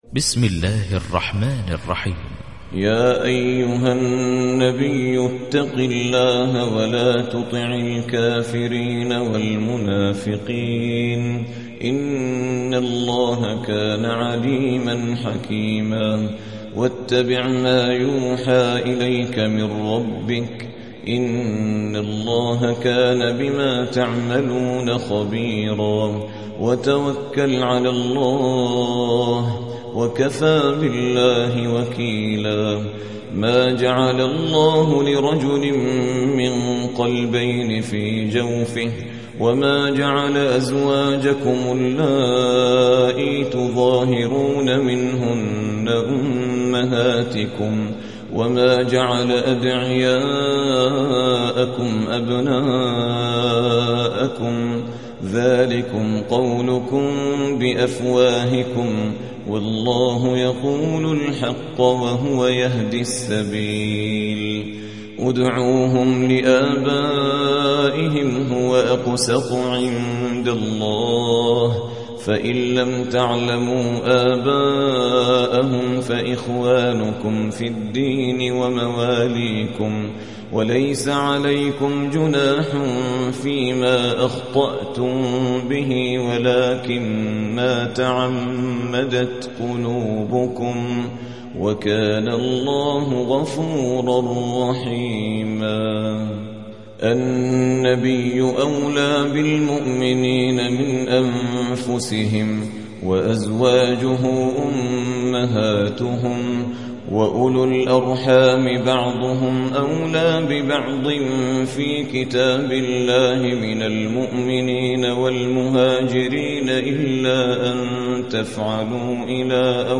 33. سورة الأحزاب / القارئ